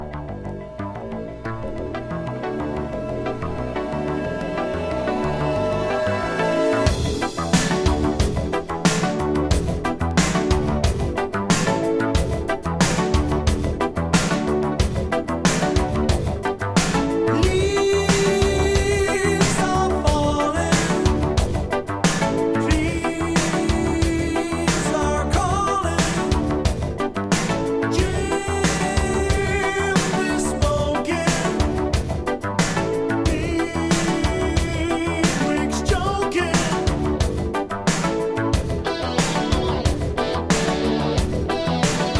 オーストラリアのスタジアムロックバンド。アルバム全体的に景気良いかんじですが、レフティなB-5を。